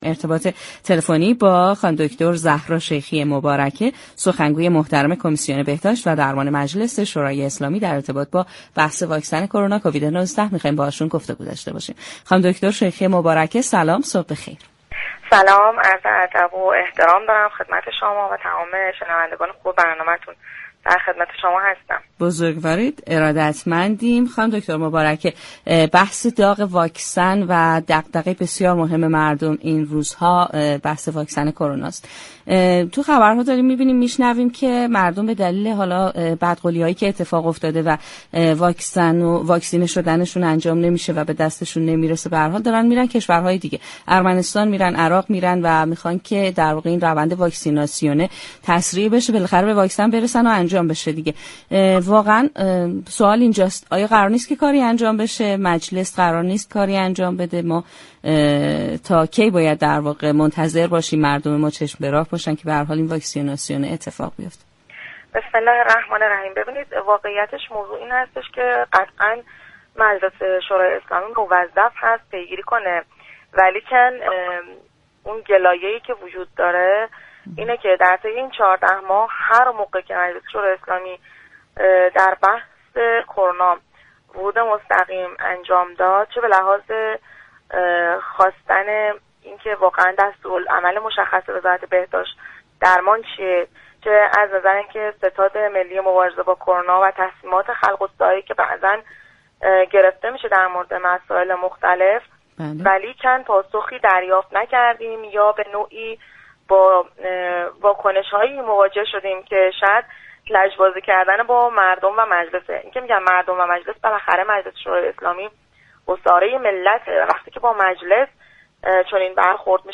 به گزارش پایگاه اطلاع رسانی رادیو تهران، زهرا شیخی مباركه سخنگوی كمیسیون بهداشت مجلس یازدهم در گفتگو با برنامه تهران ما سلامت با اشاره به اهمیت دغدغه های مردم برای تزریق واكسن حتی با قیمت سفر به كشورهای دیگر مثل عراق و ارمنستان گفت: مجلس موظف است این مشكل را پیگیری كند ولی طی 14 ماه اخیر هر وقت مجلس به بحث كرونا ورود مستقیم كرد پاسخی دریافت نكرده و حتی با واكنش‌هایی مواجه شد كه به نوعی لجبازی با مجلس و مردم است.